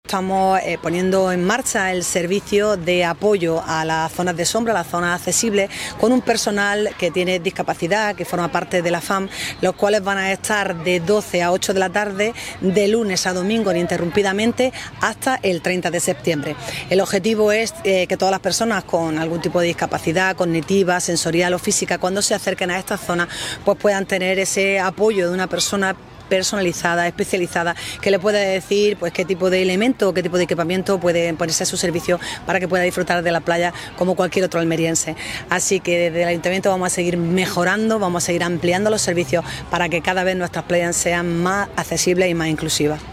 CORTE-ALCALDESA-PUNTO-ACCESIBLE-1.mp3